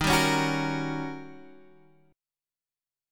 D# 7th Sharp 9th